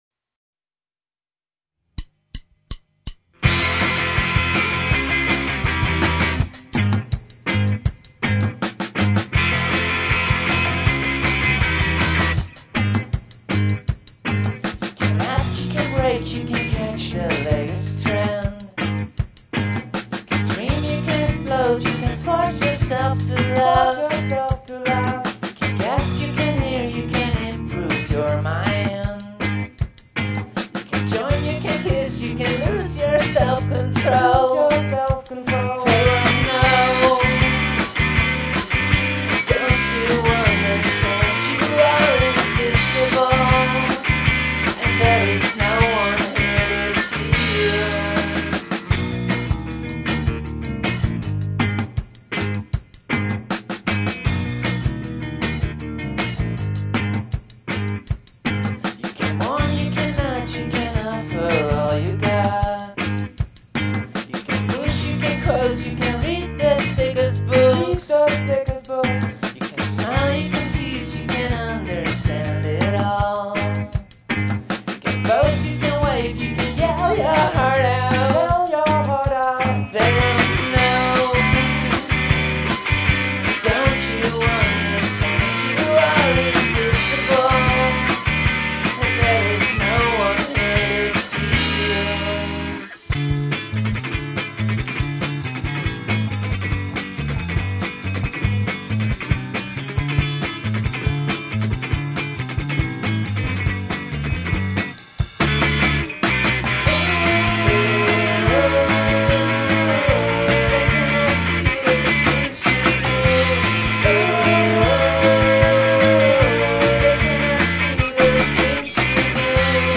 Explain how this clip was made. where: recorded at CMA (Amsterdam)